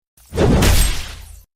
Sword Hit Sound Effect Free Download
Sword Hit